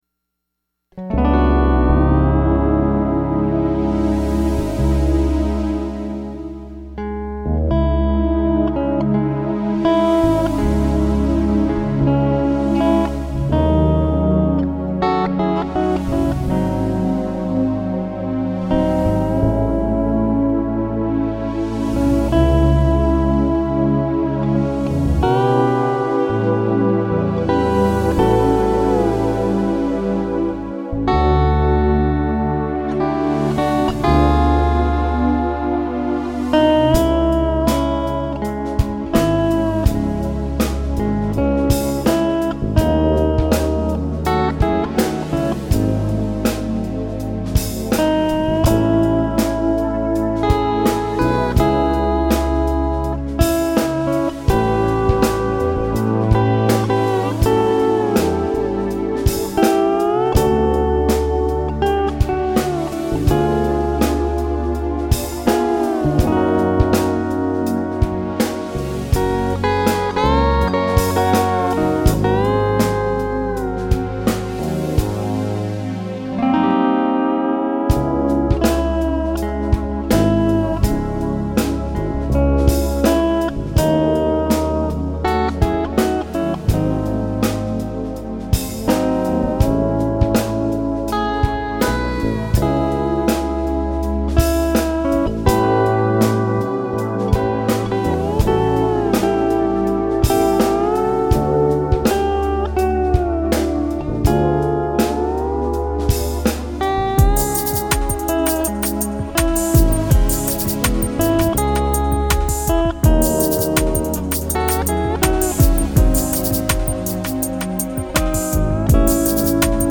It’s C6 tuning, right?